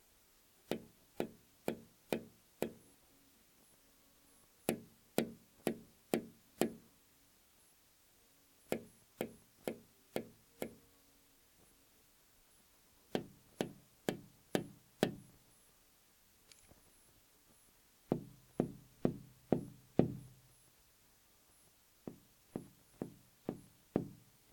Tapping on large window 120bpm
120 bang bpm ding fingernail glass large pen sound effect free sound royalty free Nature